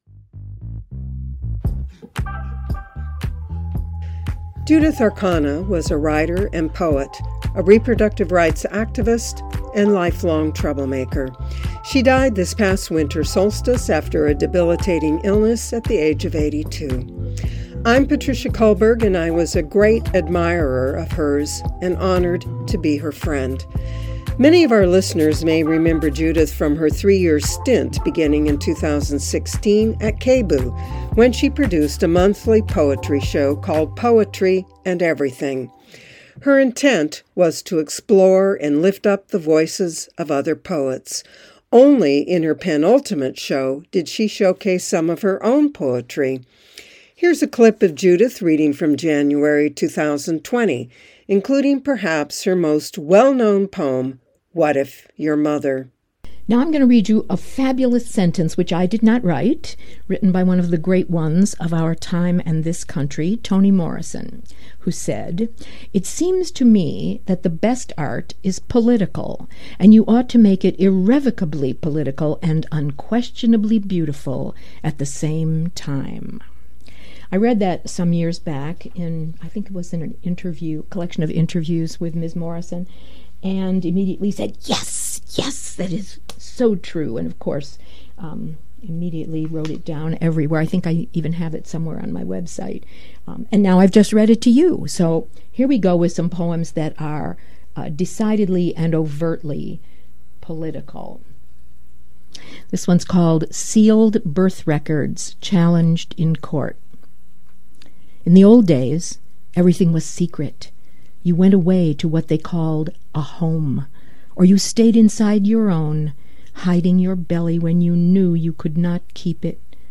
including readings of poetry and prose in her own voice.